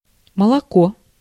Ääntäminen
Ääntäminen Tuntematon aksentti: IPA: /mjœlk/ Haettu sana löytyi näillä lähdekielillä: ruotsi Käännös Ääninäyte Substantiivit 1. молоко {n} (moloko) Muut/tuntemattomat 2. молоко́ {n} (molokó) Artikkeli: en .